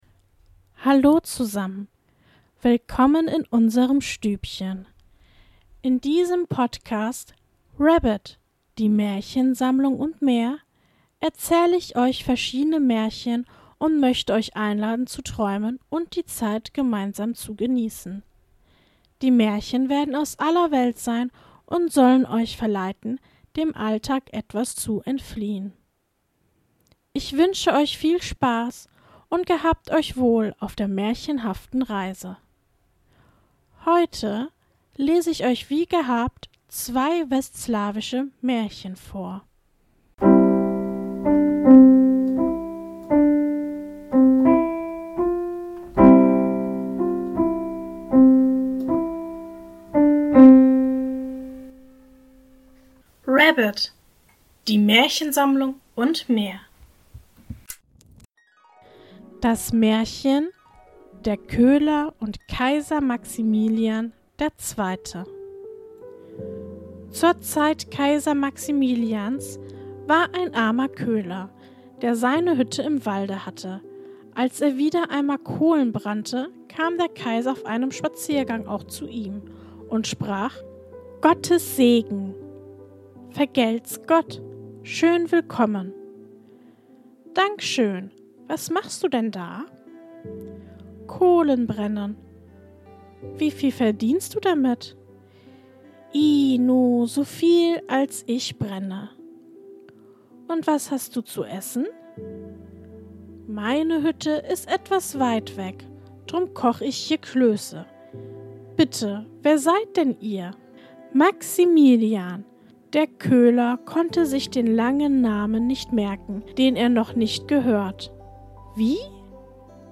In der heutigen Folge lese ich Folgendes vor: 1. Der Köhler und Kaiser Maximilian II. 2. Das Sonnenroß.